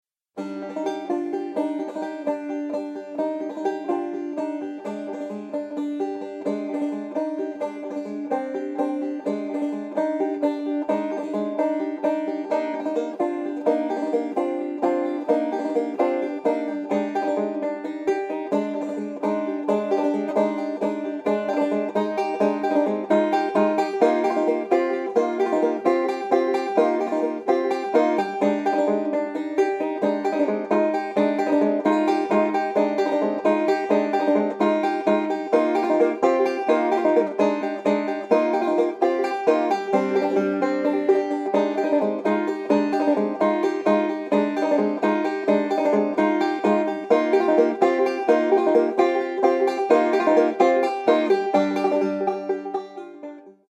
for solo banjo